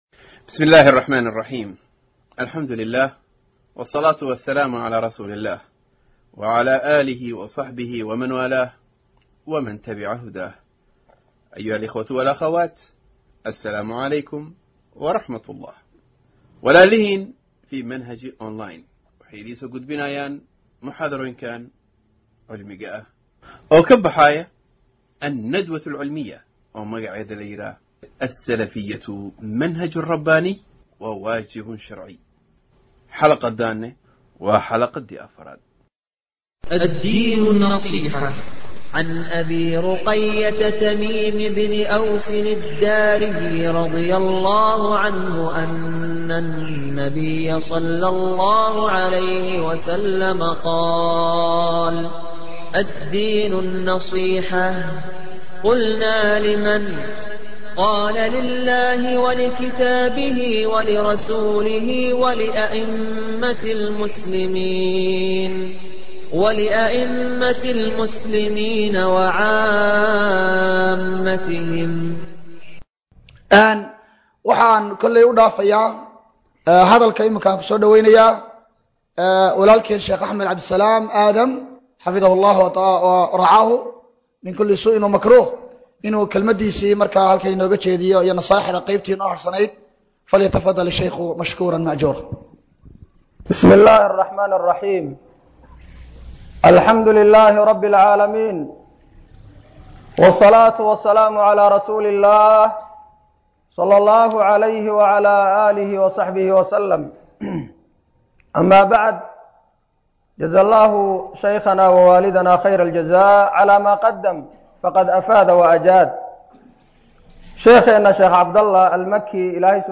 Muxaadarooyin Archives - Manhaj Online |